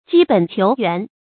積本求原 注音： ㄐㄧ ㄅㄣˇ ㄑㄧㄡˊ ㄧㄨㄢˊ 讀音讀法： 意思解釋： 從根本上探求。